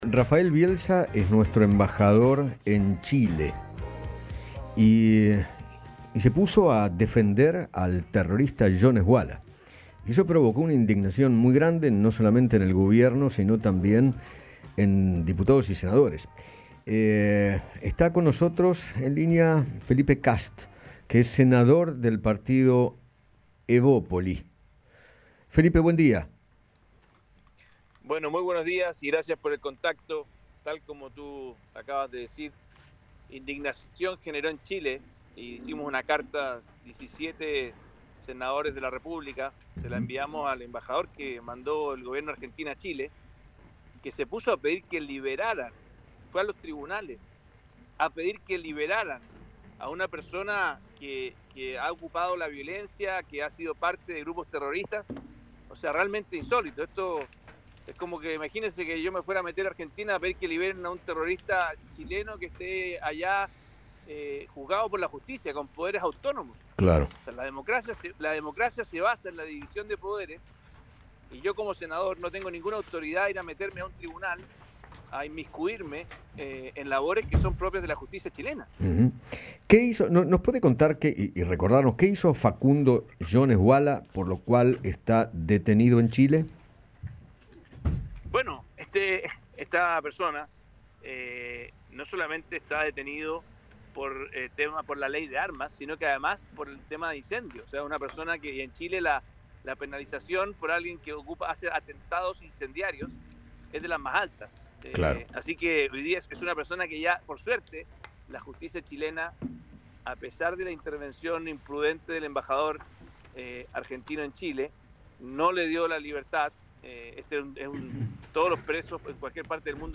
Felipe Kast, senador chileno, dialogó con Eduardo Feinmann sobre el apoyo del embajador argentino en Chile al líder mapuche, Facundo Jones Huala, durante una audiencia judicial en la que se debatía un pedido para que recupere su libertad.